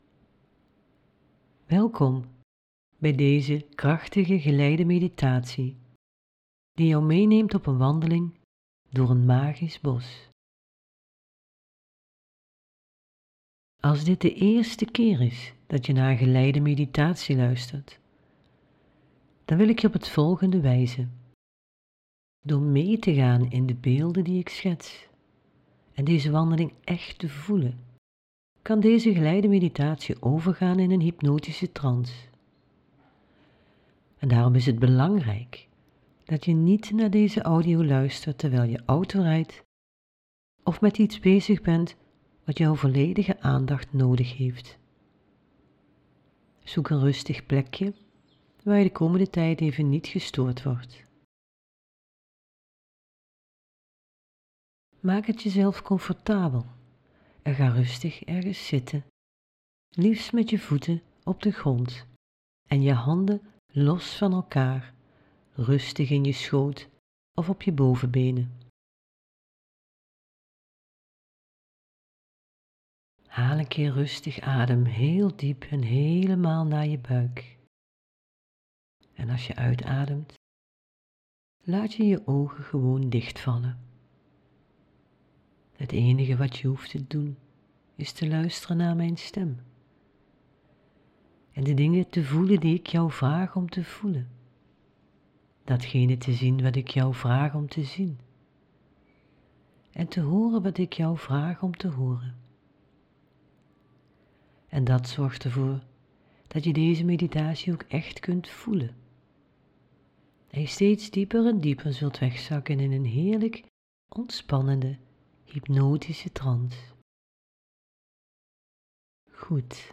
Hier is je hypnose audio.